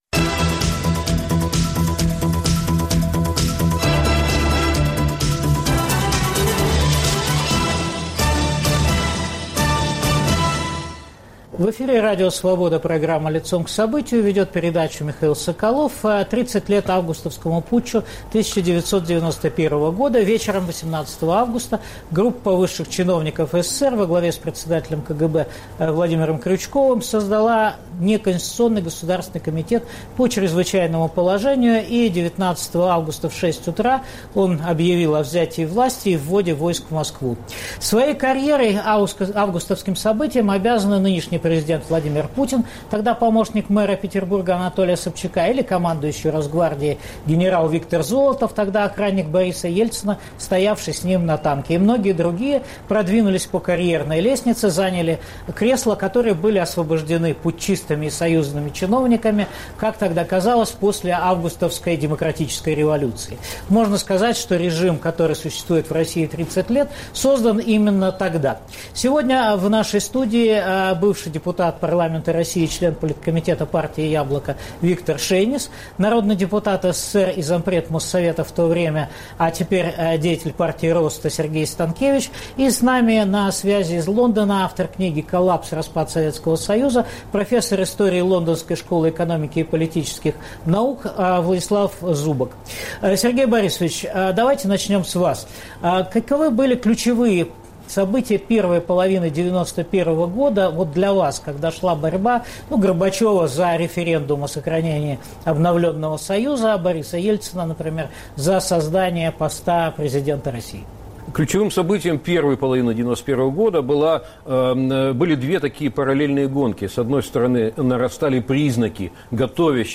Обсуждают участники событий